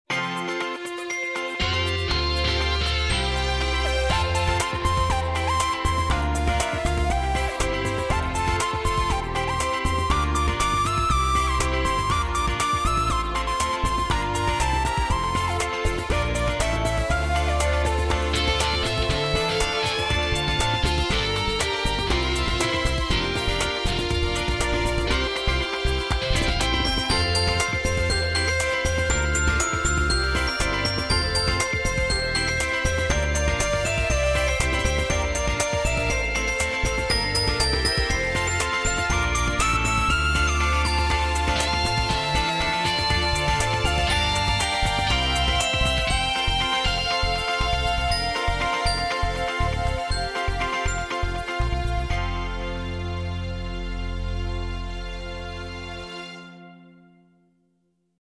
Жанр: "Acapella"